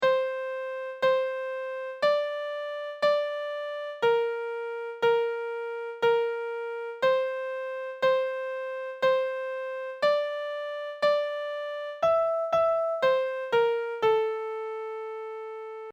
Secondary tune: